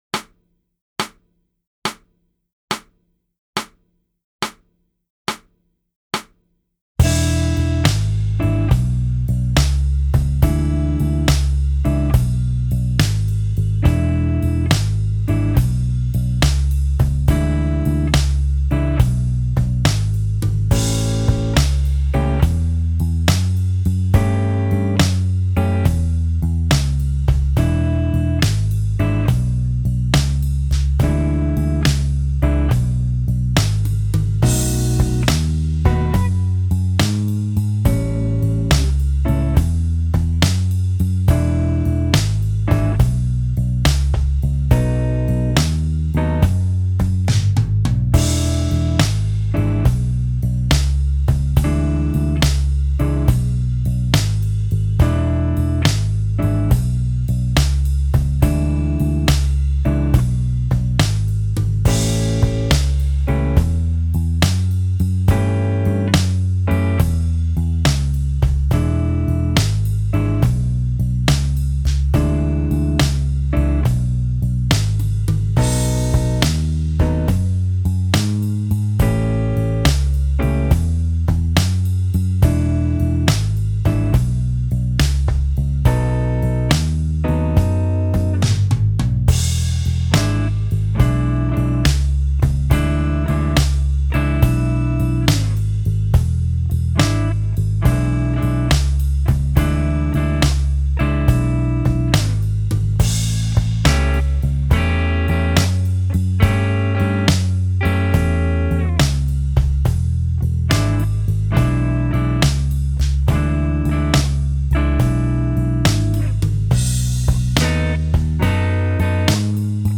Blues Phrasing & Expression Sheet Music